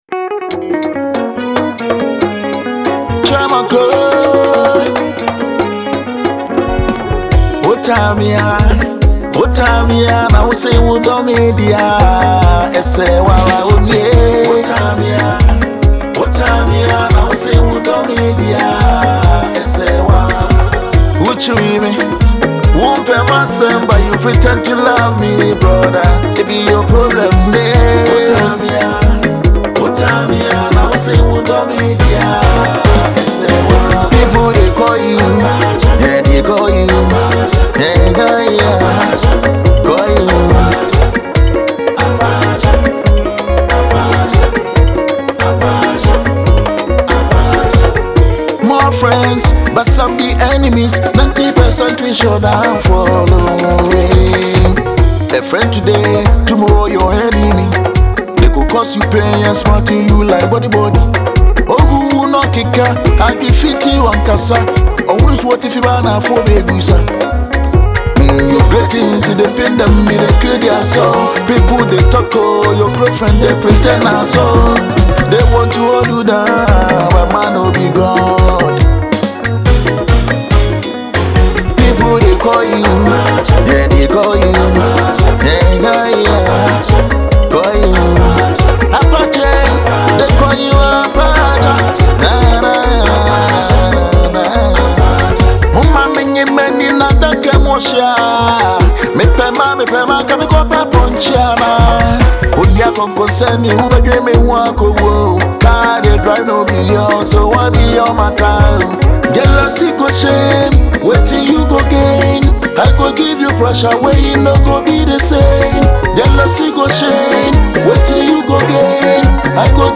Genre: Afrobeat / Afropop